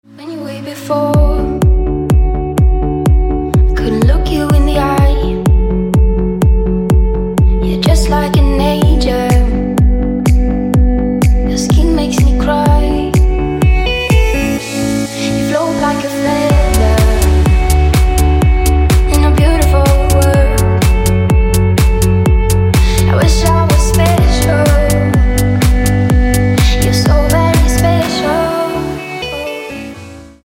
• Качество: 256, Stereo
женский вокал
deep house
dance
Electronic
club
Chill
vocal